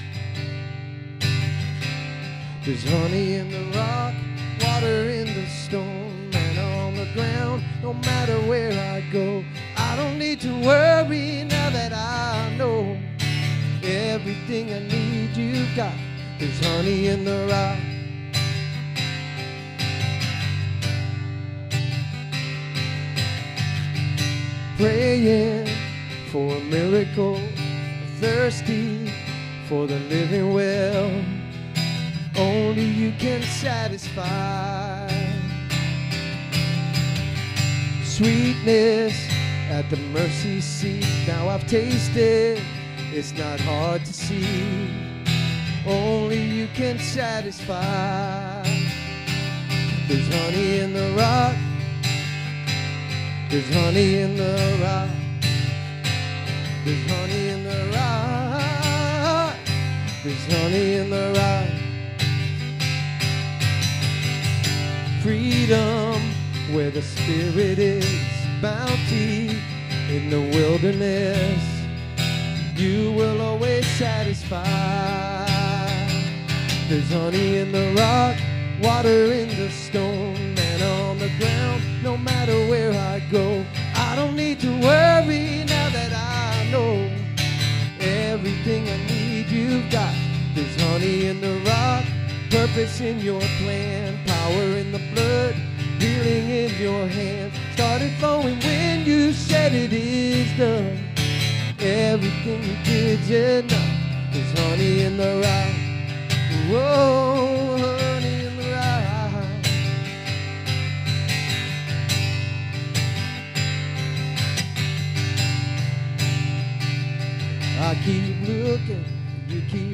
SERMON DESCRIPTION "How Much?" explores Jesus’ powerful lesson from the widow’s offering, revealing that true generosity is measured not by the amount given, but by the faith and sacrifice behind it.